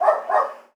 dog_bark_small_05.wav